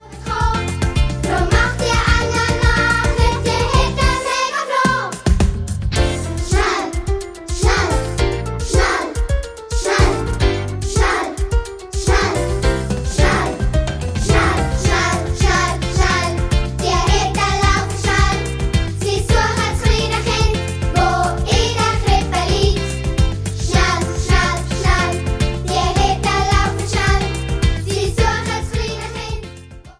Weihnachtsmusical